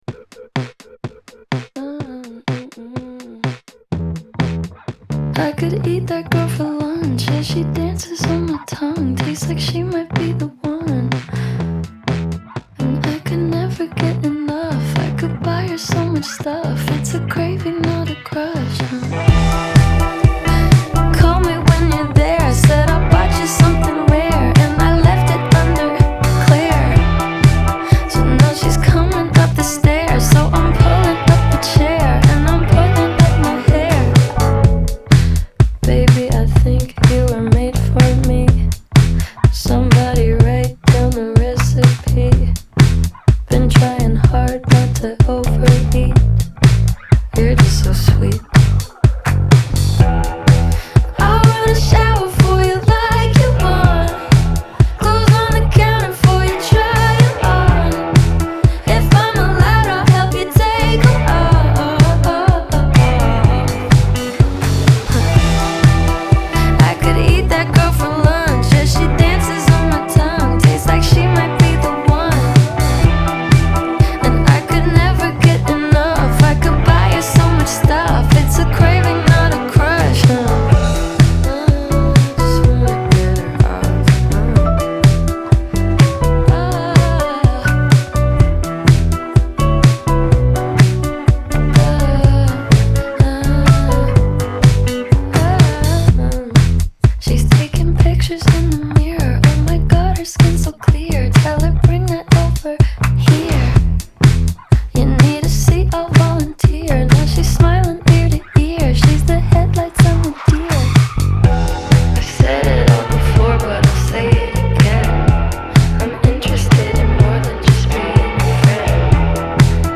• Жанр: Indie